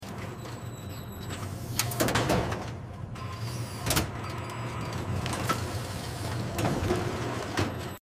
Butcher machine